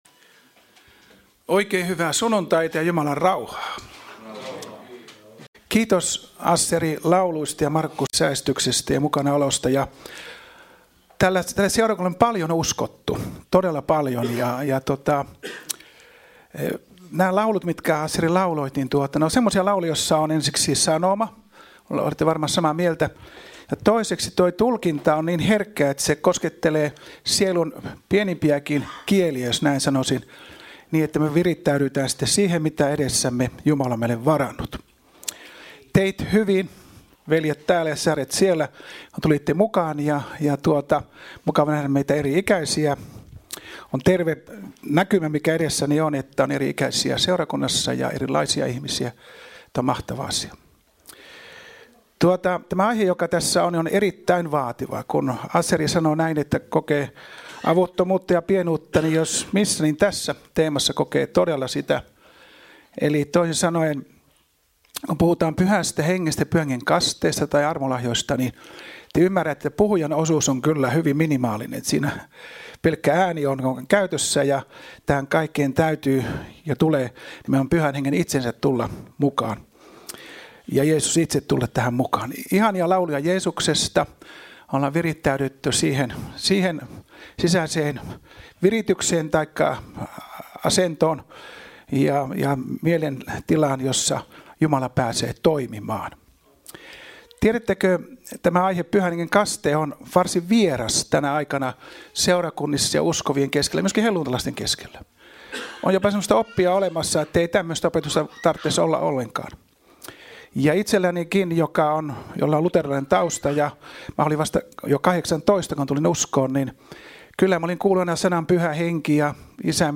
Service Type: Raamattutunti